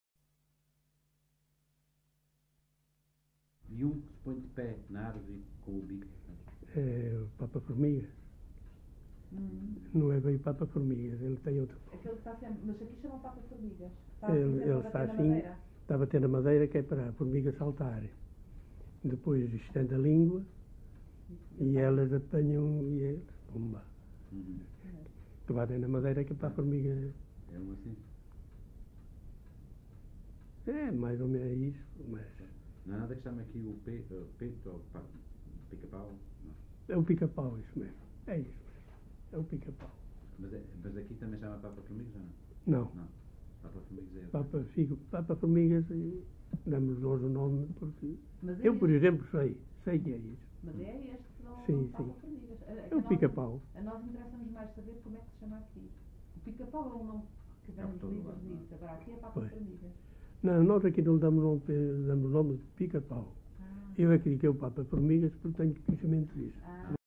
LocalidadeFigueiró da Serra (Celorico da Beira, Guarda)